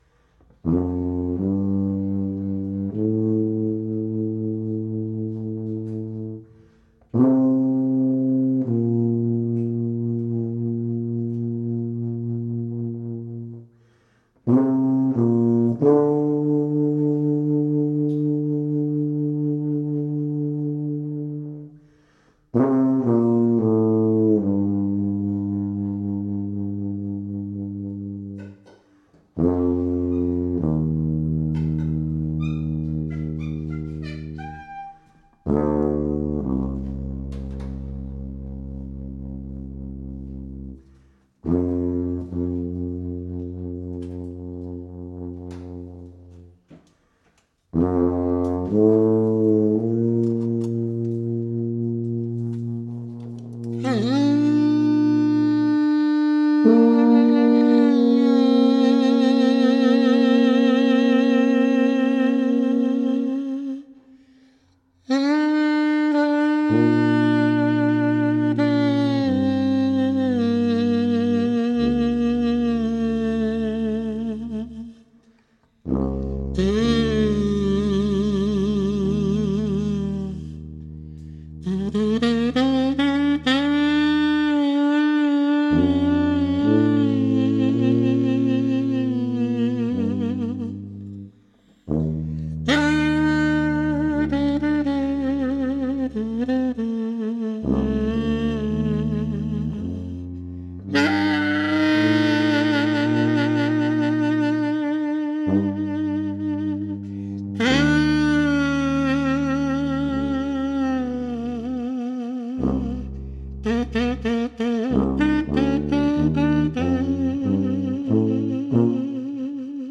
Recorded live at ABC NoRio in Manhattan September 28, 2014
tuba
baritone saxophone